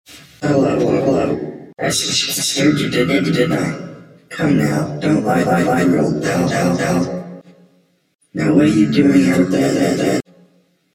I decided the first one was a little choppy, so I redid it and fiddled with some of the settings.
Springtrap and Deliah Voice Acting (REDUX)